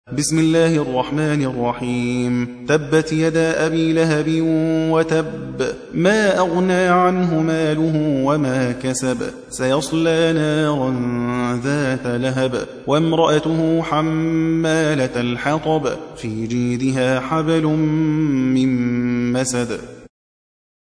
111. سورة المسد / القارئ